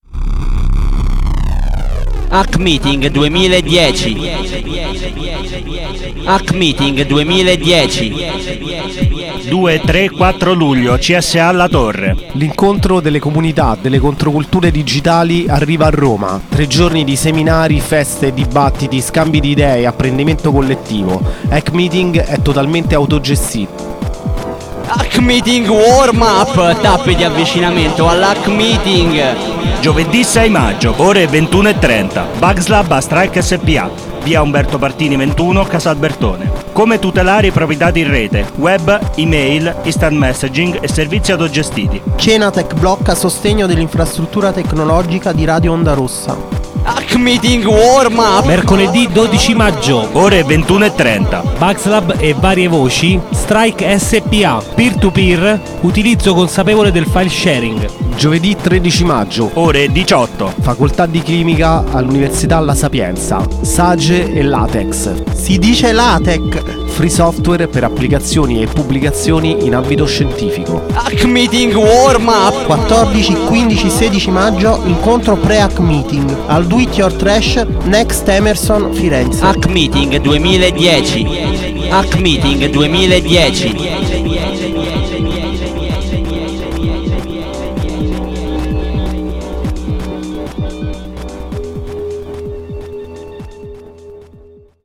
Spot Radio WarmUp Roma maggio prima parte